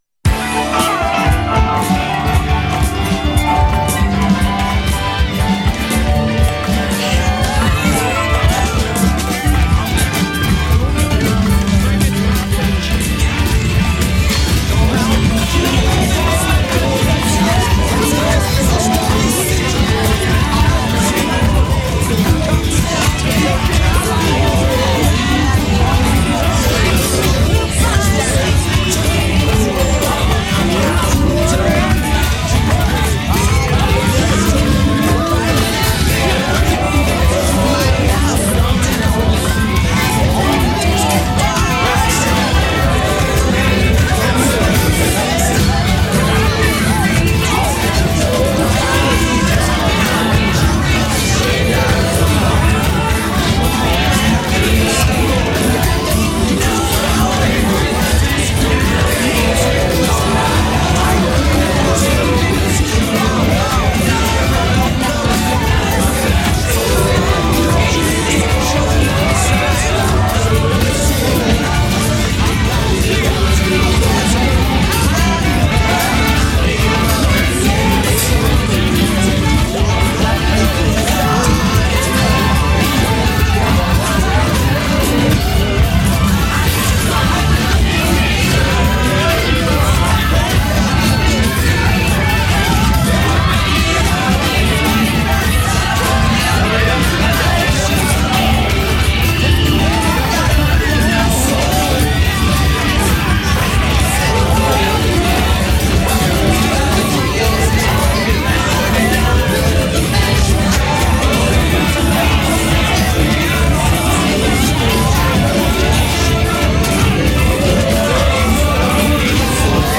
It’s ten songs–in this case, all from the 70’s to narrow things down a bit–all bundled together into one ball of confusion.
All ten songs start from their beginning.